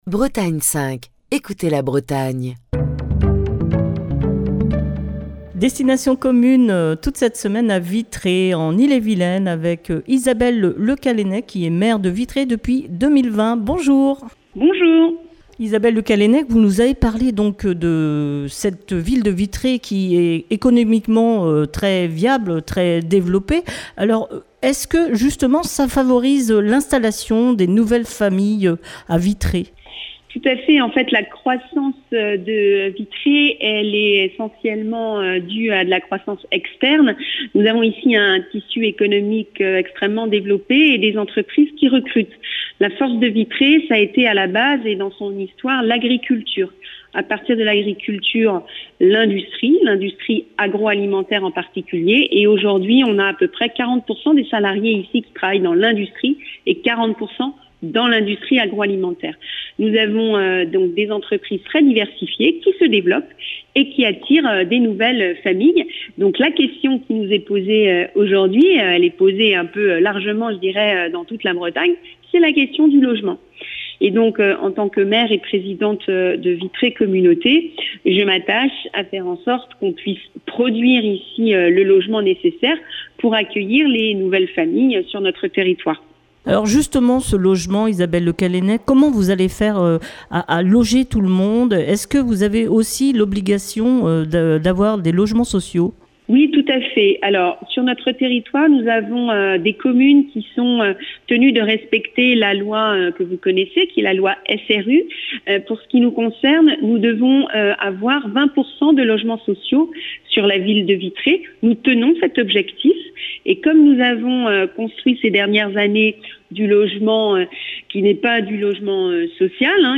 est au téléphone avec la maire de Vitré, Isabelle Le Callennec